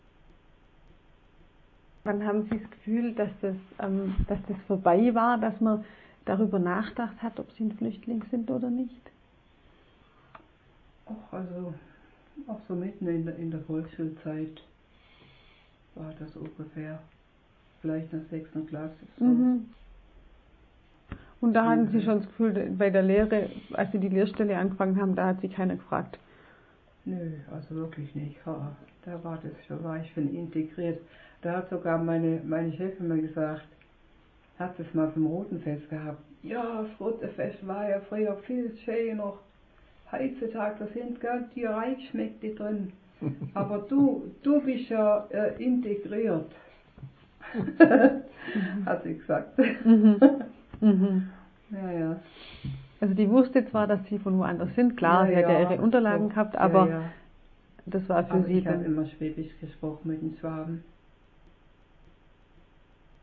Audio 2: Interviewausschnitt